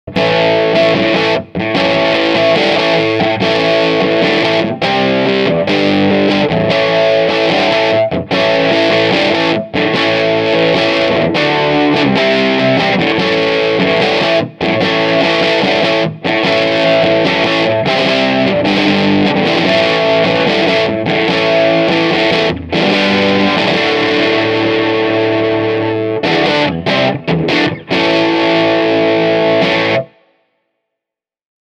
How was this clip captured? Reverb and echo have been added at the mixing stage.